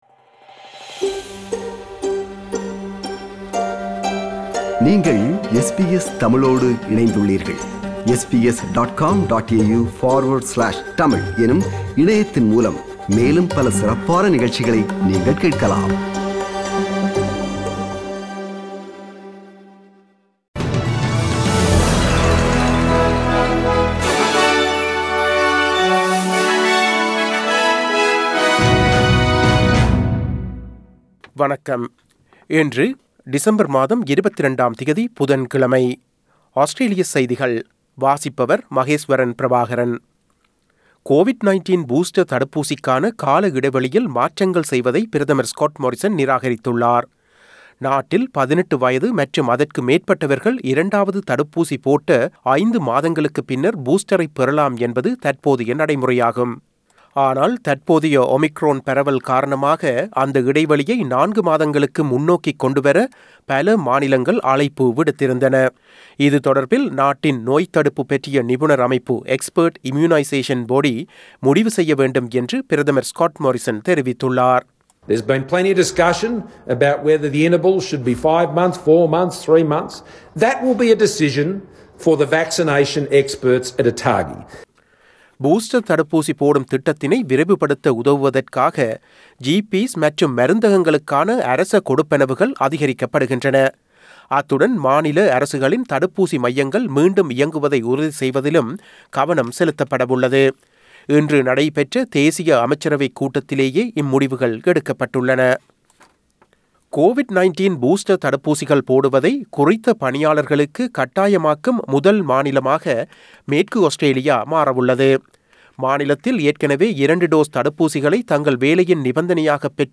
Australian news bulletin for Wednesday 15 December 2021.